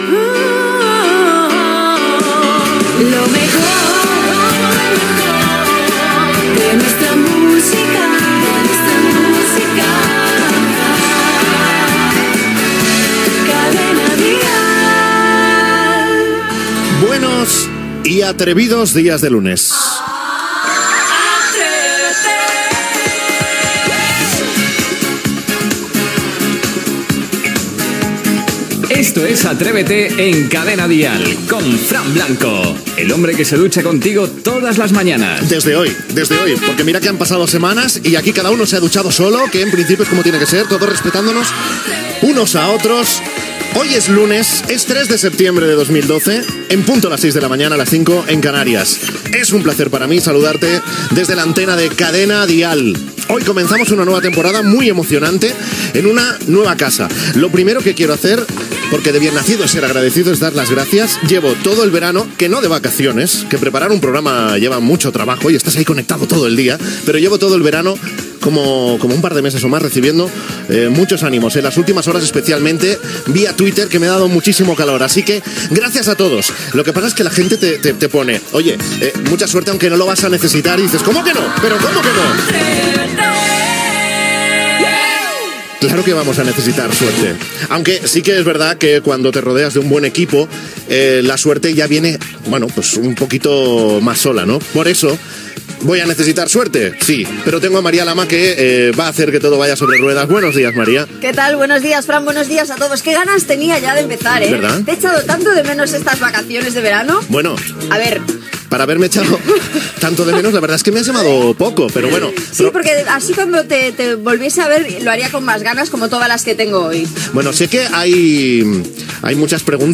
Indicatiu de la ràdio, careta del programa, data, presentació del primera edició renovada del programa, repàs als temes del dia, telèfon del programa i tema musical
Entreteniment